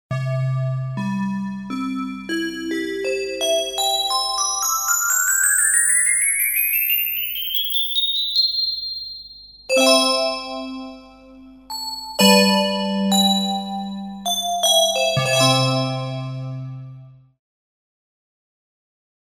Звуки ёлки
На этой странице собраны звуки, связанные с ёлкой и новогодней атмосферой: от мягкого шума хвои до перезвона украшений.
Звук зажигания праздничных огней